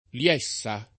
Liessa [ l L$SS a ]